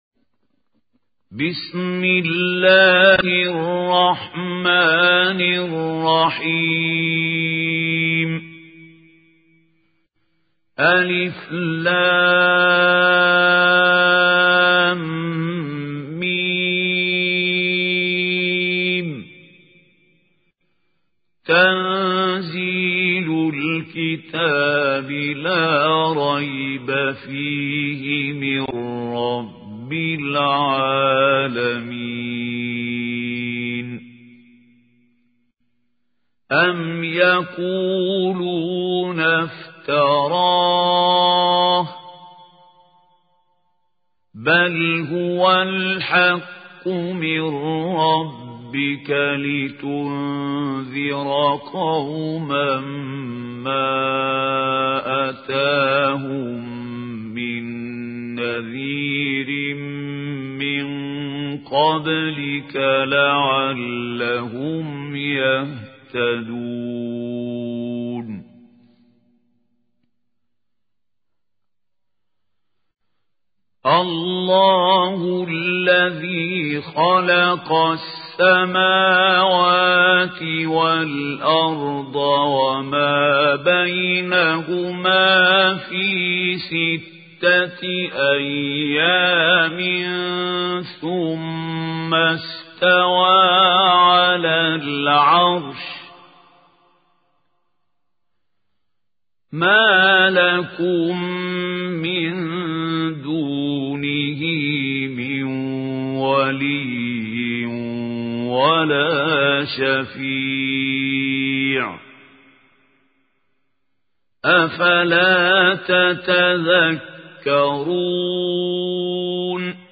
اسم التصنيف: المـكتبة الصــوتيه >> القرآن الكريم >> الشيخ خليل الحصري
القارئ: الشيخ خليل الحصري